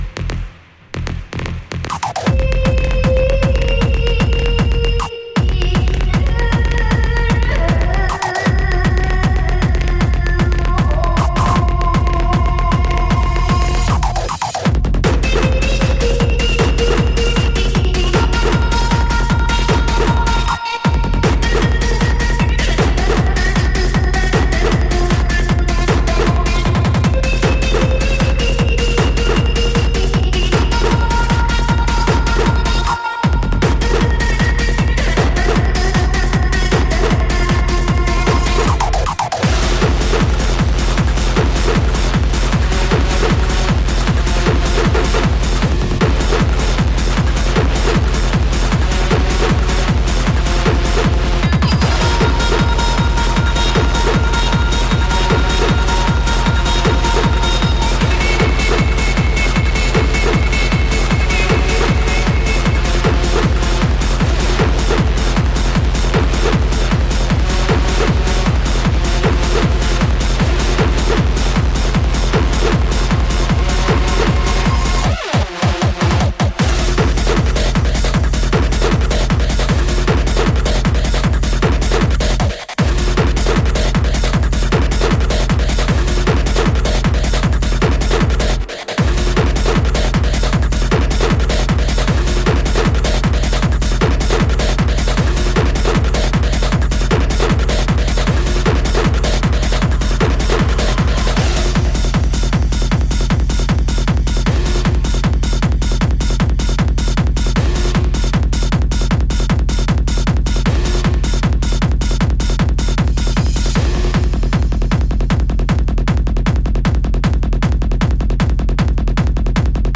Techno, Hardcore